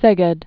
(sĕgĕd)